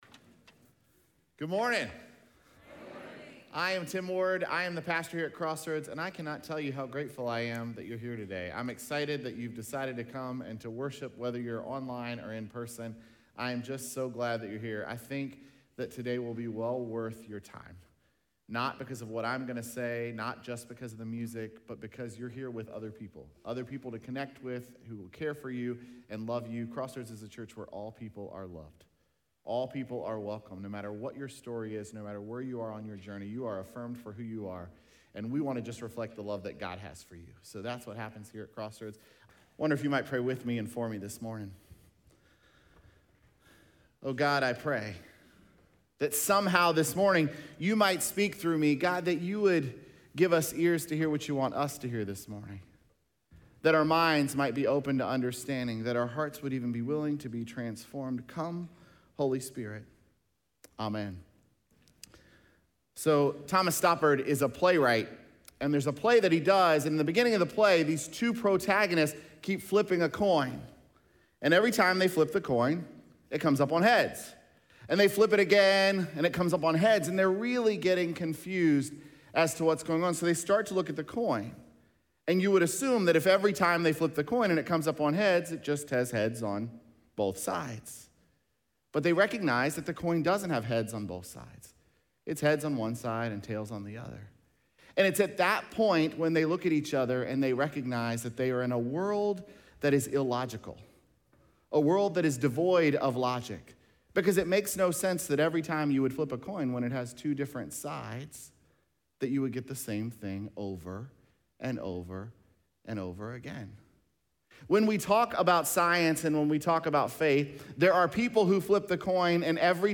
Sermons
Sunday morning message